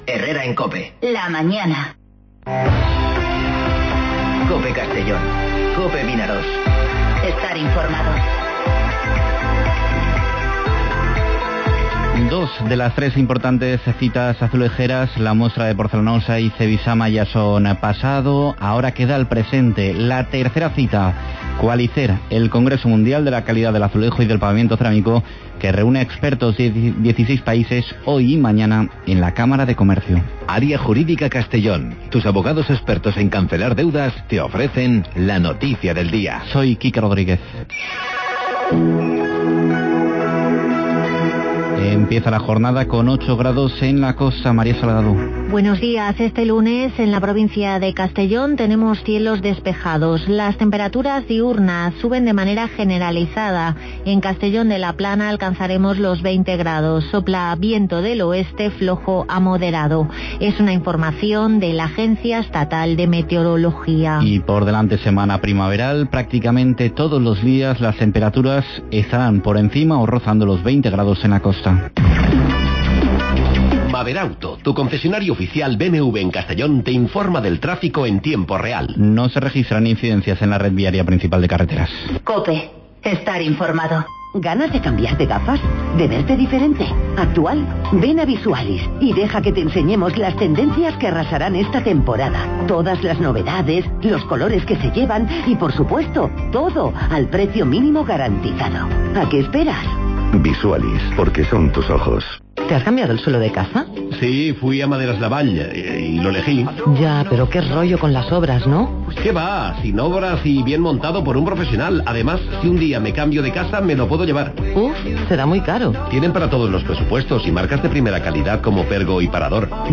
Informativo Herrera en COPE Castellón (10/02/2020)